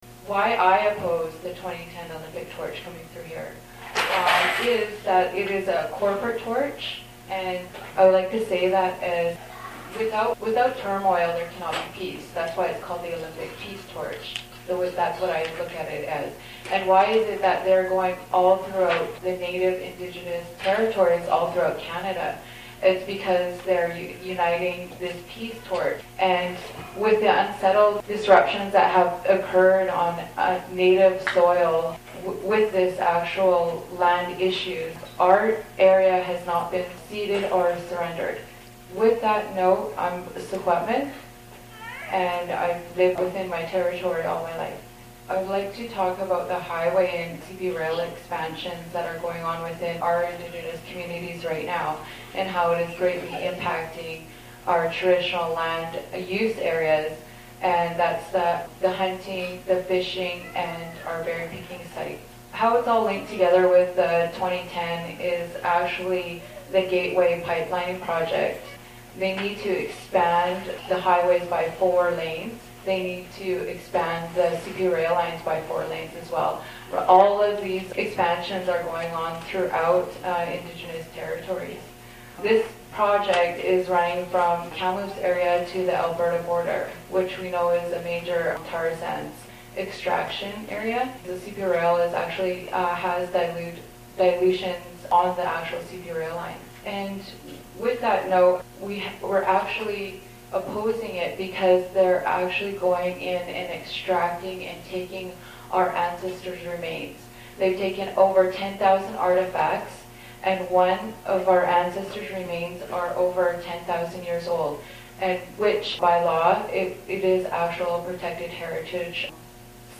at a event in Montreal in december 2009 before the protest against the torch rally organized by the Montreal chapter of Peoples Global Action (PGA)